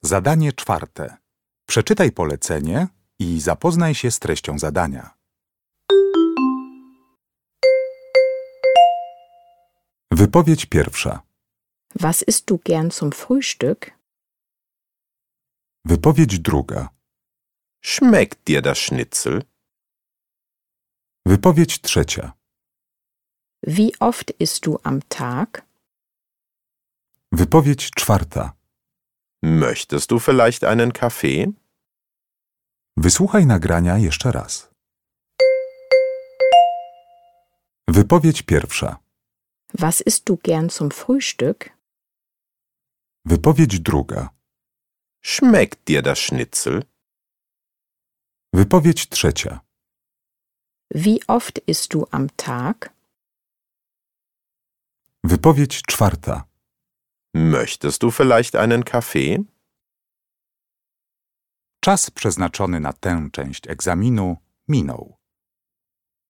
Uruchamiając odtwarzacz z oryginalnym nagraniem CKE usłyszysz dwukrotnie cztery wypowiedzi na temat żywienia (4.1.–4.4.).